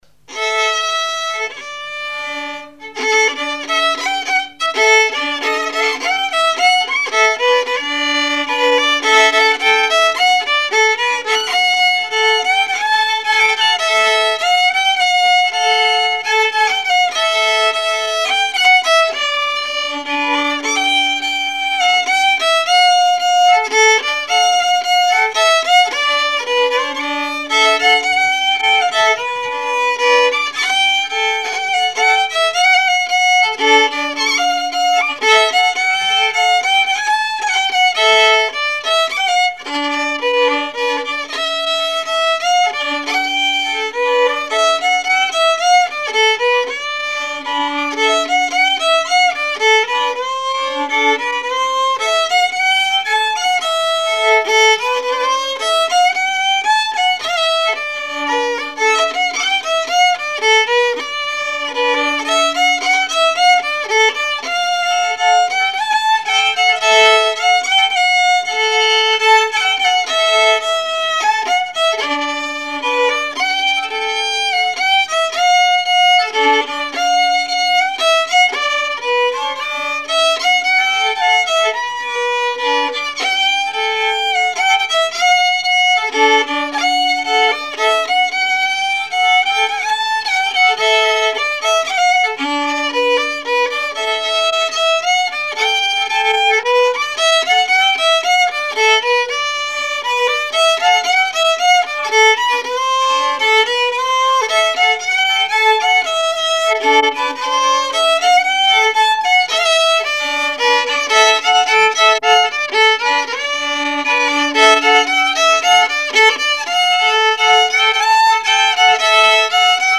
Genre strophique
Activité du violoneux
Pièce musicale inédite